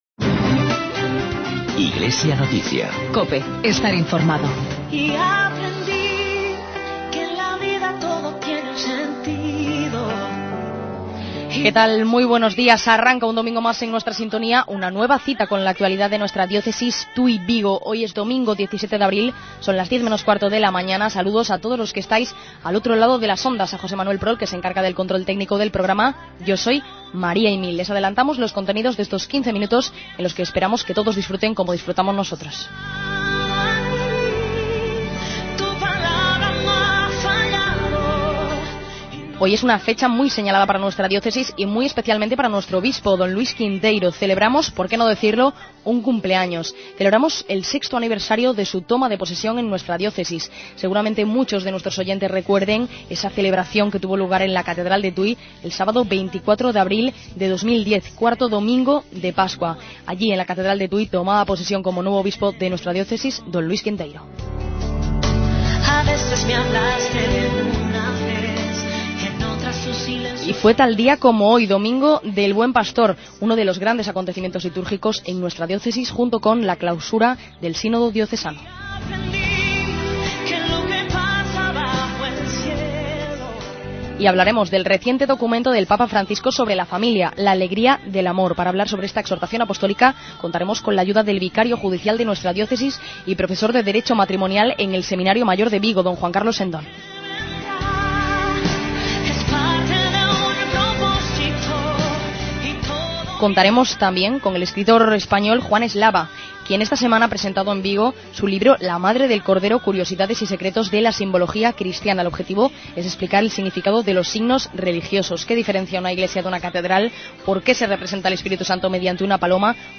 AUDIO: Informativo Diocesano.